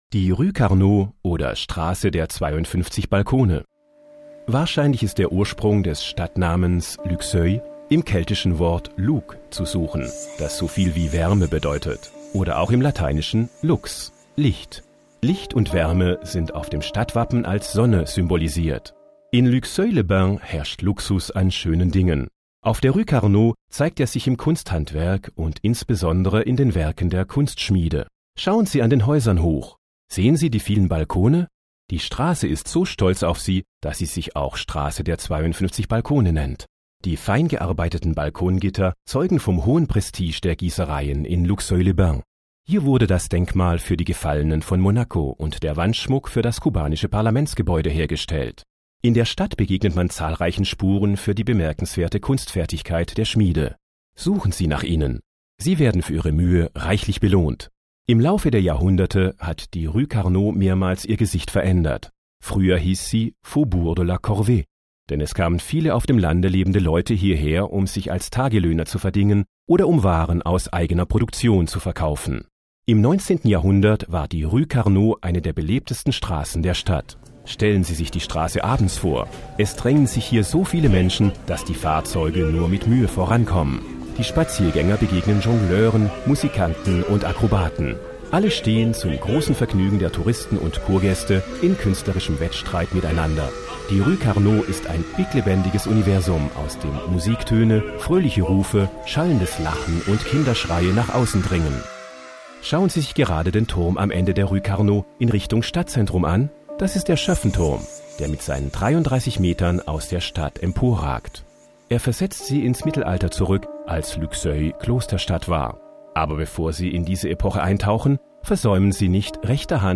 Balade audio – 02 La rue Carnot ou la rue aux 52 balcons
Explications audio